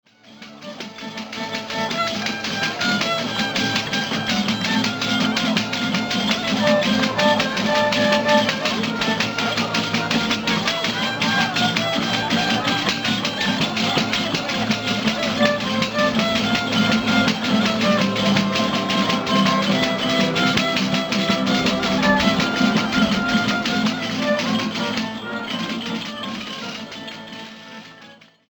Pandero de Verdiales
Es uno de los panderos ibéricos que más sonajas incorpora, llegando incluso a reforzar su sonoridad con cascabeles. Toma su nombre del fandango popular al que sirve de acompañamiento en la provincia de Málaga.
Se interpreta junto a otros instrumentos como el violín, los crótalos, la guitarra y el laúd, que acompañan al canto en la demostración del baile de bandera.
Panda de Villanueva de la Concepción (Málaga)
verdiales.mp3